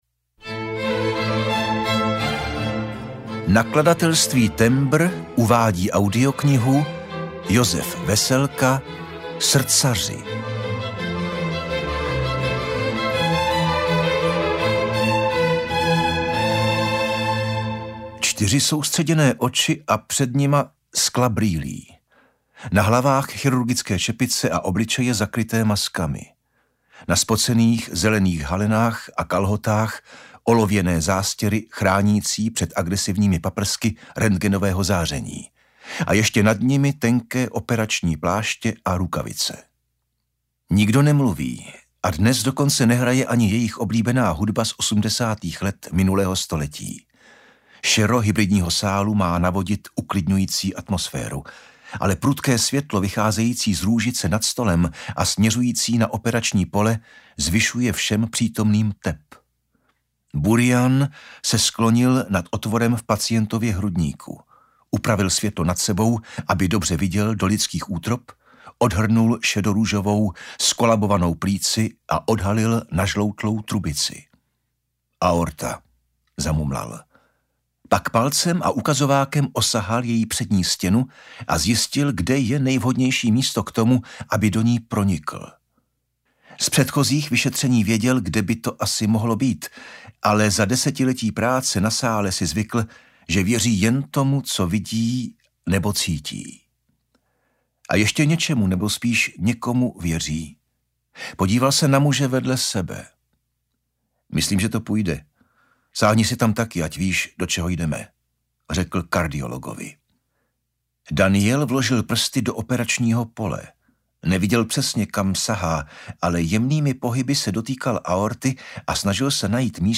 Srdcaři audiokniha
Ukázka z knihy
• InterpretVladislav Beneš